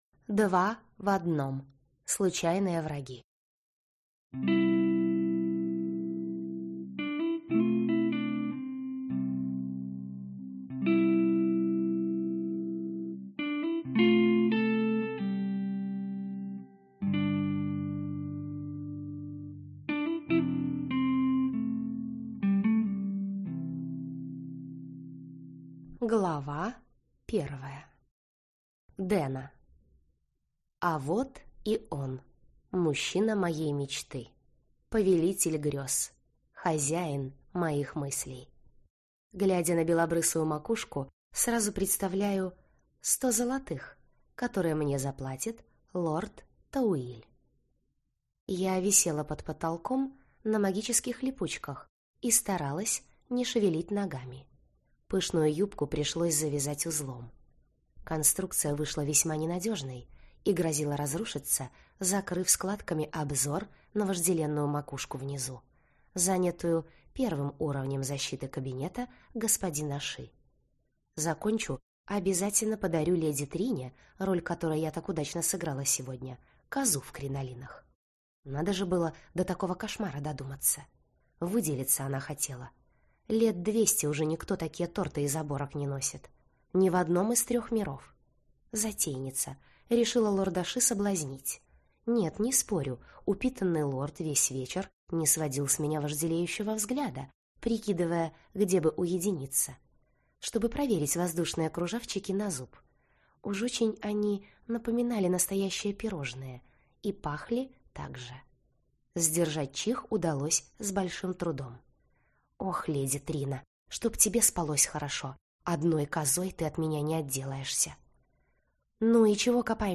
Аудиокнига Два в одном. Случайные враги | Библиотека аудиокниг